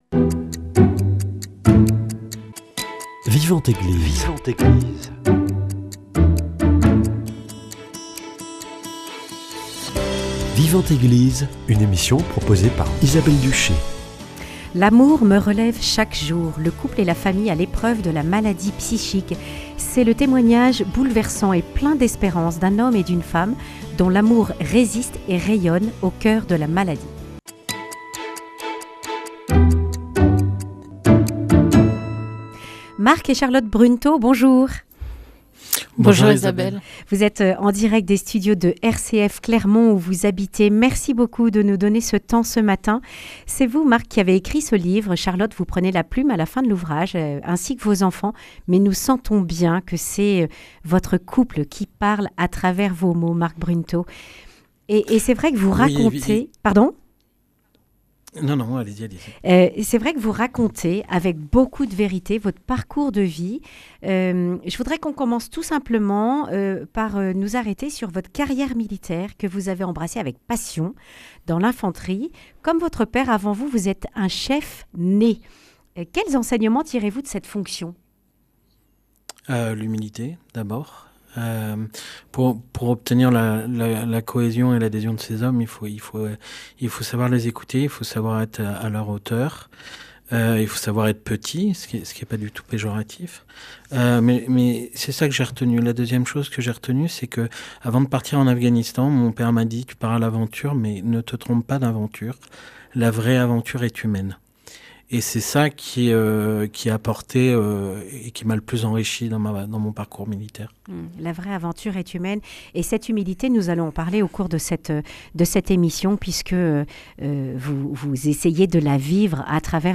Le couple à l’épreuve de la maladie psychique, un témoignage criant de vérité et d’espérance